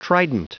Prononciation du mot trident en anglais (fichier audio)
Prononciation du mot : trident